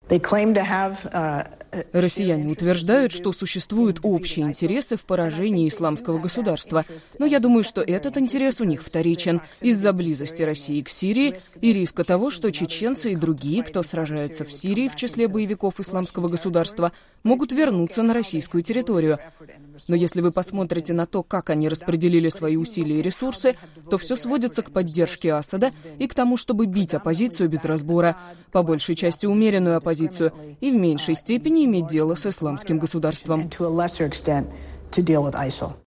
Помощник президента США по национальной безопасности Сьюзан Райс в интервью Фариду Закариа из Си-эн-эн заявила, что Россия борется с "Исламским государством" в Сирии лишь отчасти.